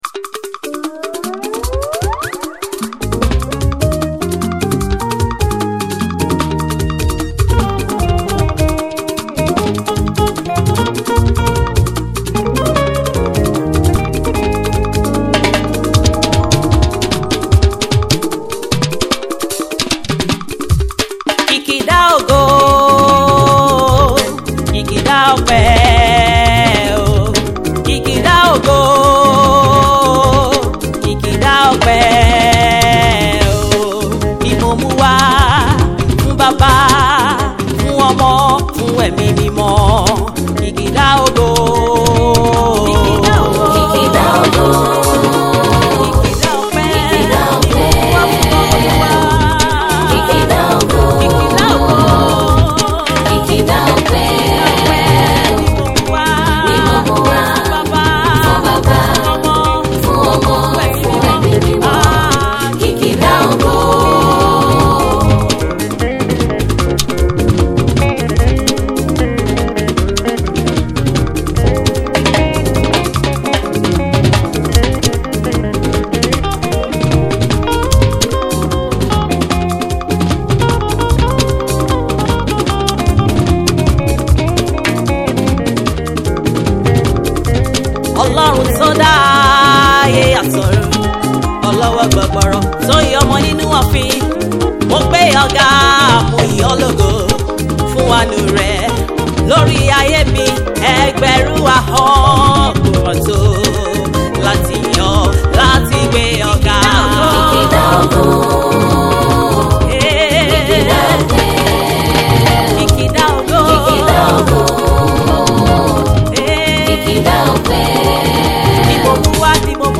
gospel minister and worship leader
a praise medley song